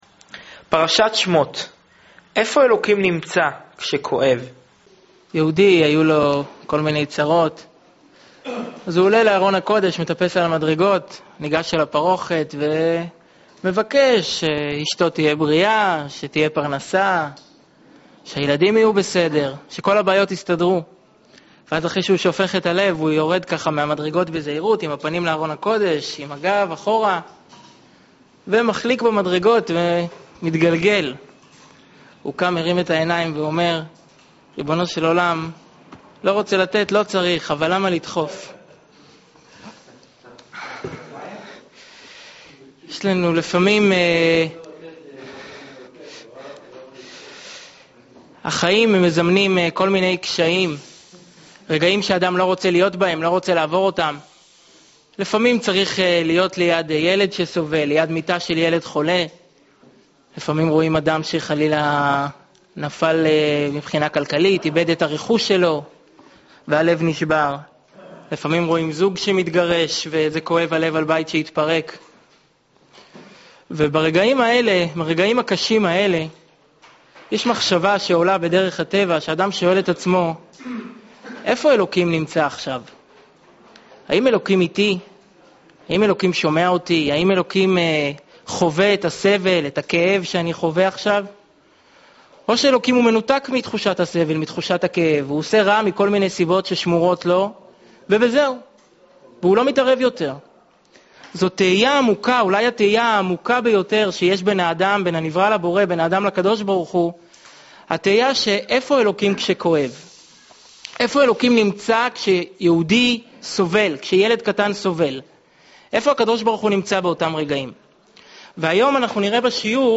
שיעור מרגש לפרשת שמות
שנמסר בביהכנ"ס חב"ד בראשל"צ